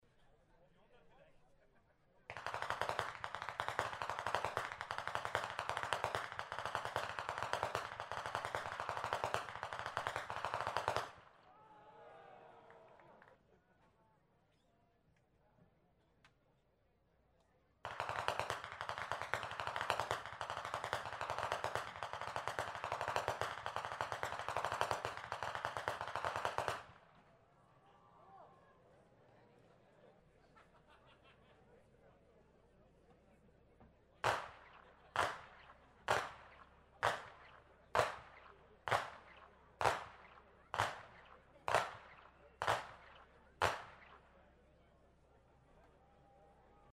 50. Ainringer Gemeindepreisschnalzen in Perach am 02.02.2025